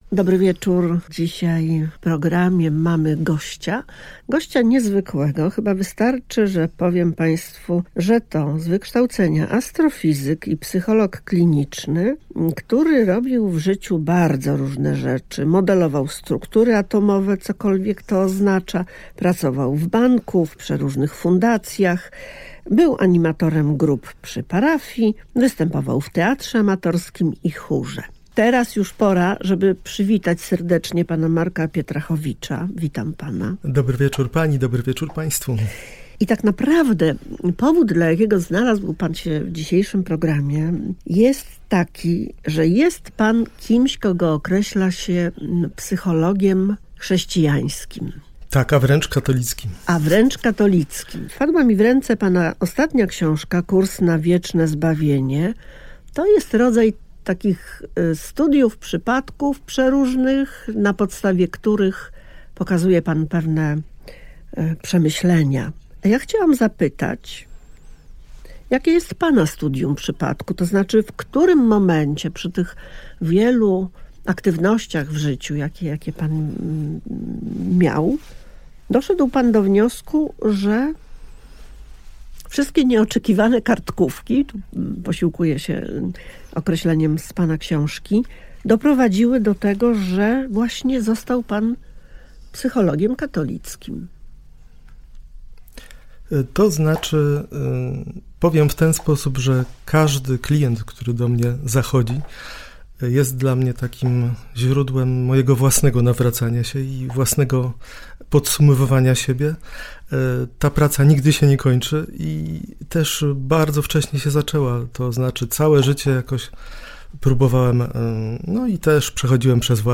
17.08.2025 Wiara. Poradnik dla wątpiących - rozmowa wokół książki "Psychoterapia Ewangelią"